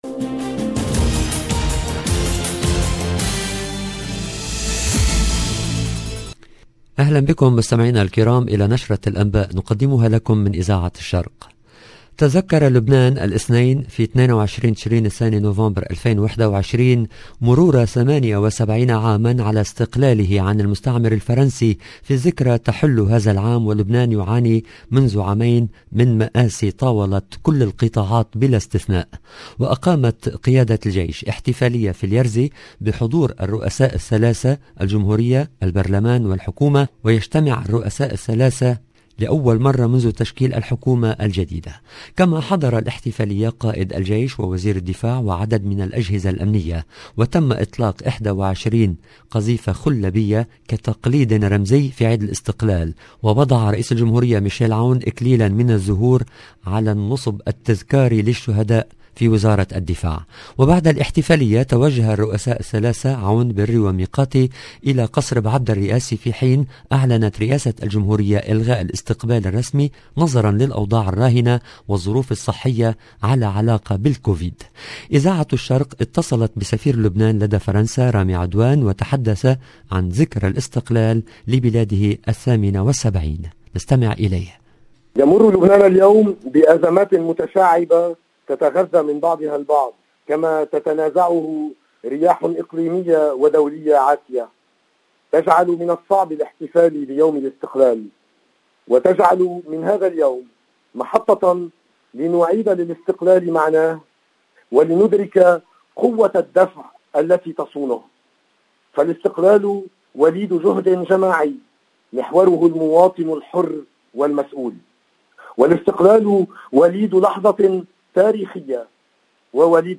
LE JOURNAL DU SOIR EN LANGUE ARABE DU 22/11/21